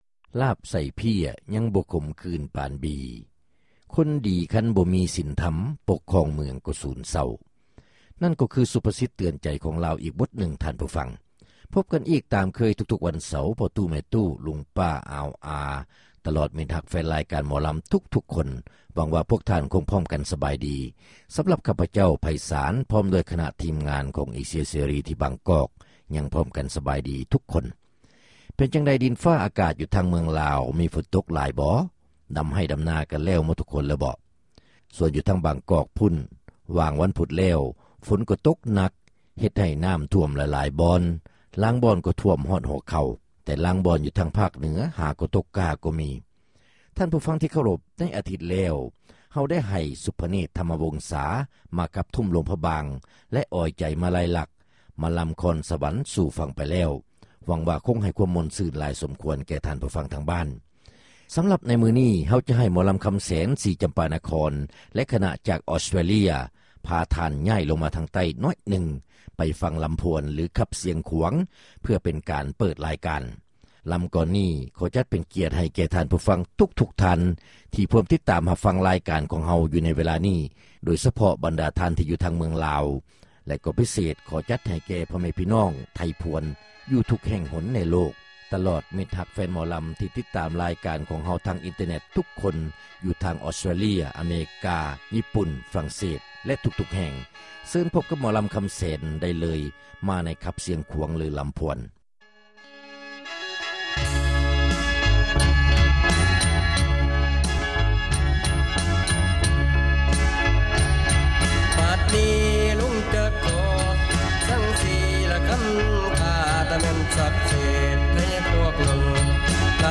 ຣາຍການໜໍລຳ ປະຈຳສັປະດາ ວັນທີ 15 ເດືອນ ກໍຣະກະດາ ປີ 2005